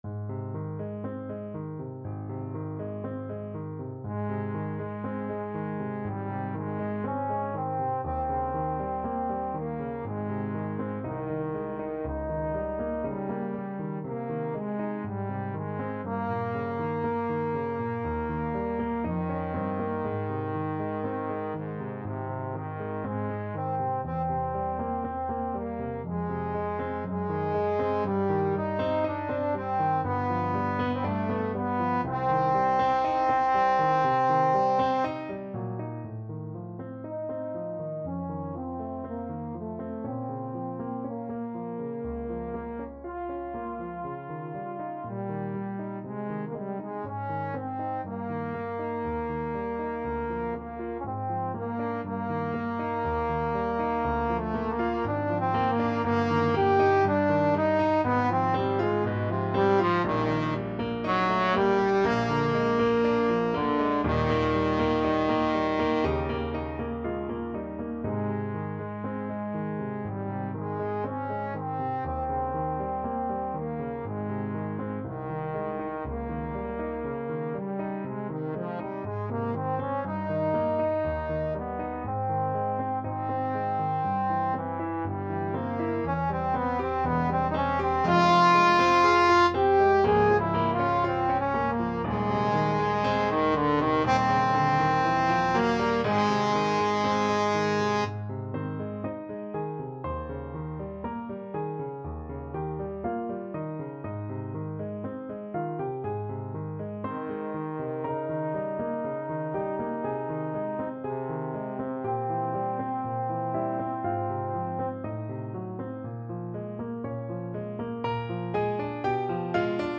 4/4 (View more 4/4 Music)
~ = 100 Andante quasi Adagio
Trombone  (View more Advanced Trombone Music)
Classical (View more Classical Trombone Music)